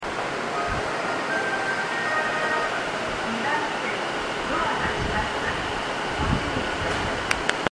スピーカーはNational型が使用されており音質がとても良いです。
発車メロディー